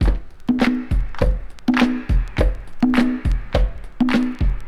• 103 Bpm High Quality Breakbeat Sample F# Key.wav
Free breakbeat - kick tuned to the F# note. Loudest frequency: 542Hz
103-bpm-high-quality-breakbeat-sample-f-sharp-key-om3.wav